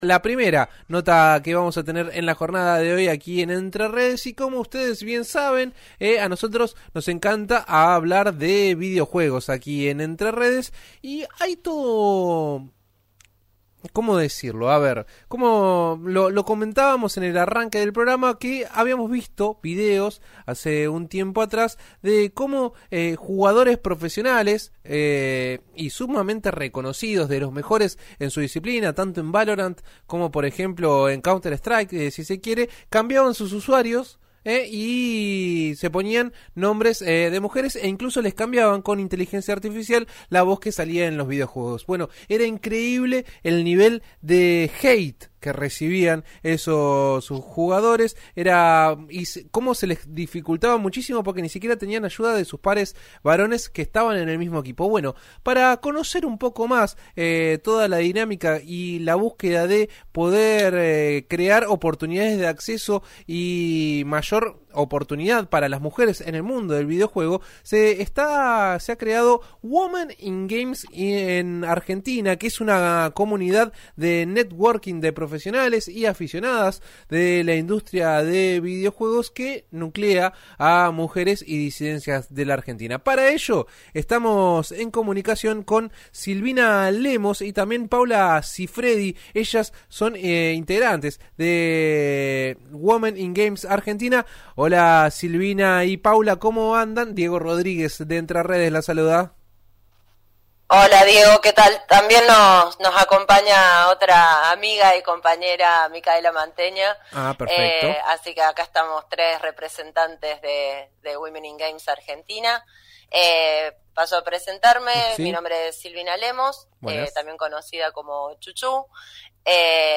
Escuchá a las integrantes de Women in Games Argentina, en RÍO NEGRO RADIO:
En una entrevista en el programa «Entre Redes» de RIO NEGRO RADIO, miembros de la agrupación hablaron sobre su recorrido y las luchas que llevan adelante para lograr la igualdad de género en la industria de los videojuegos.